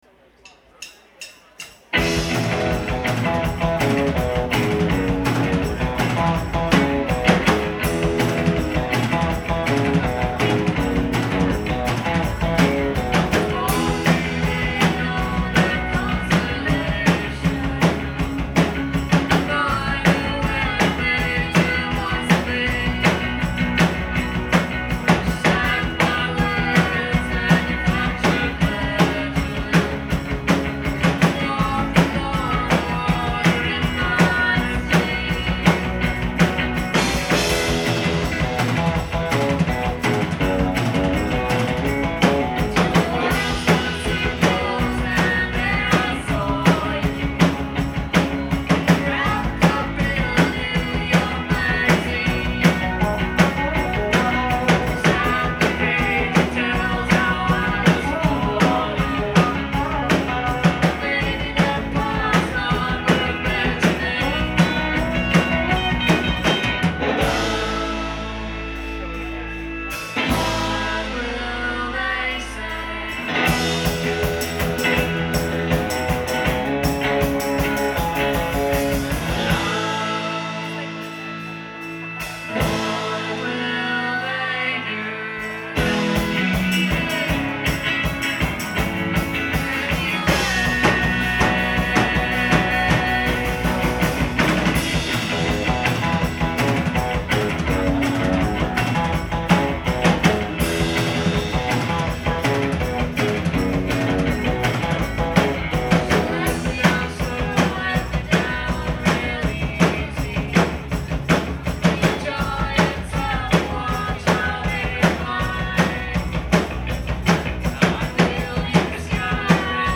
Live at The Paradise
in Boston, Mass.